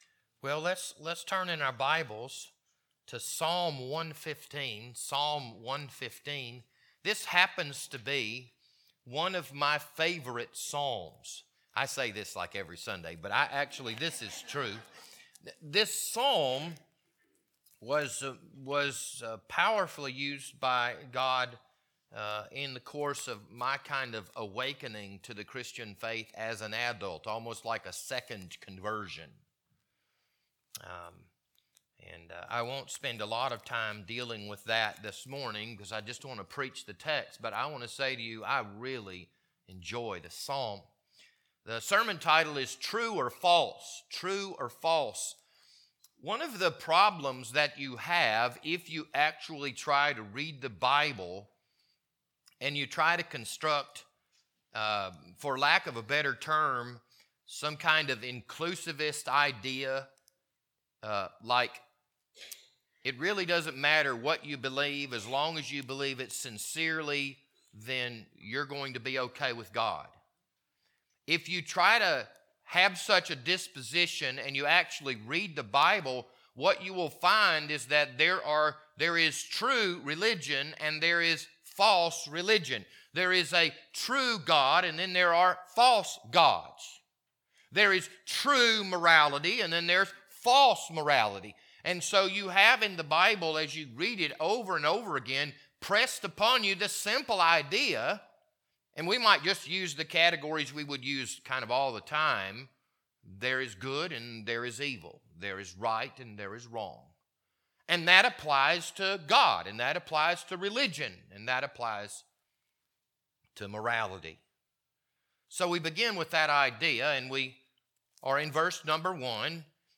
This Sunday morning sermon was recorded on April 2nd, 2023.